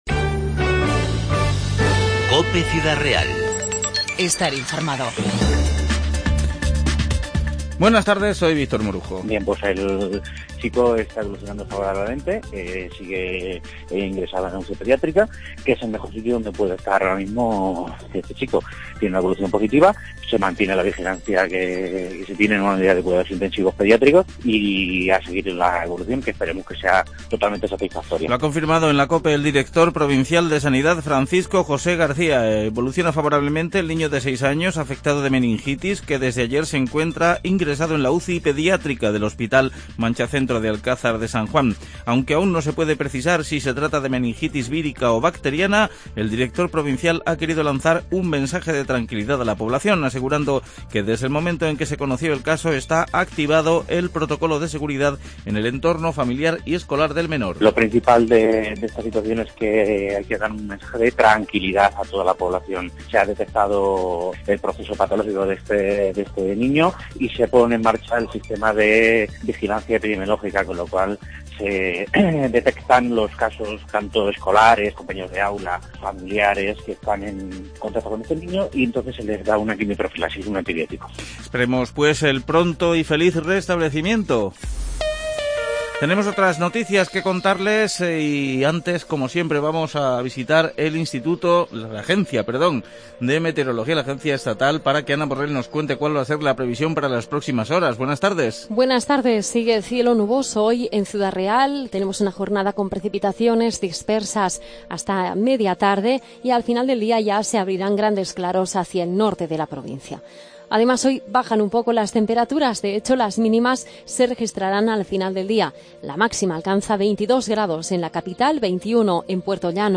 INFORMATIVO 13-10-15